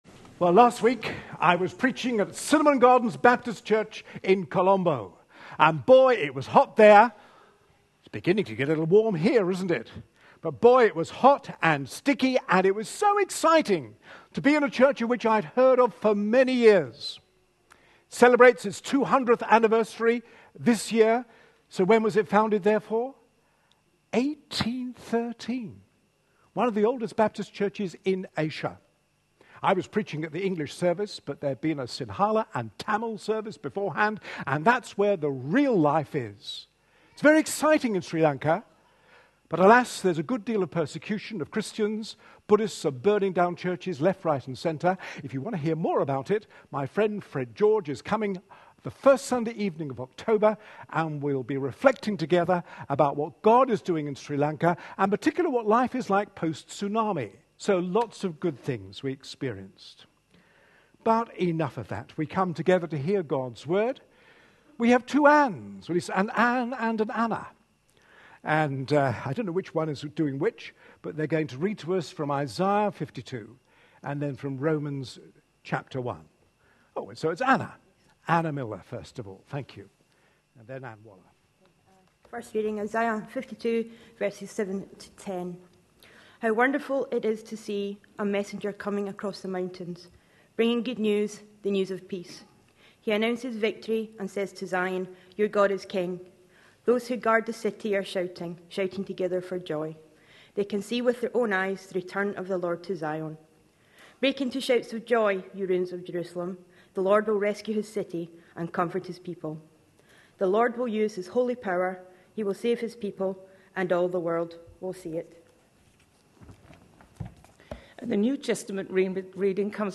A sermon preached on 1st September, 2013.